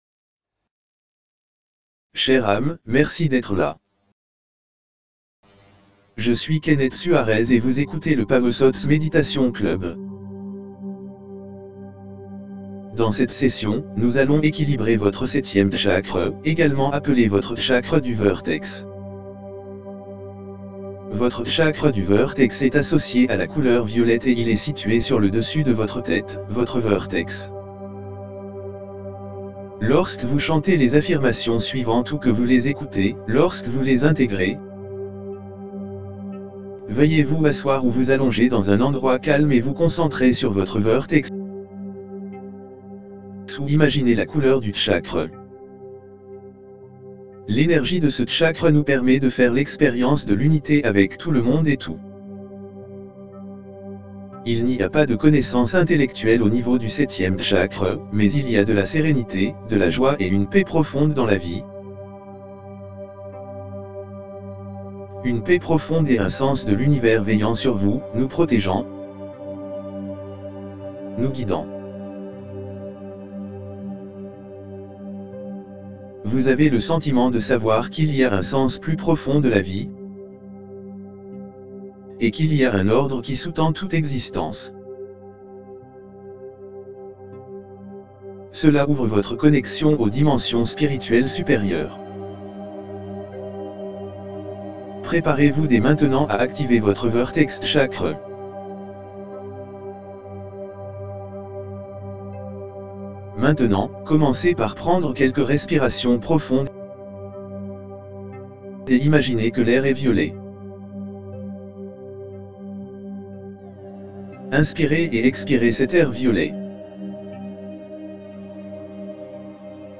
Chakra du Vertex ➤ Méditation Guidée
Avec Solfège 963 Hz
Beats binauraux | Tambours Navajos